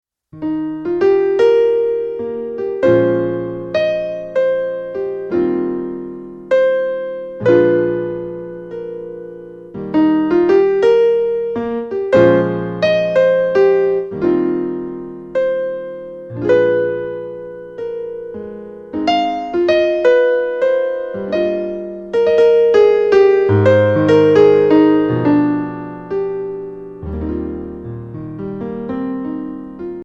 Instrumental Album Download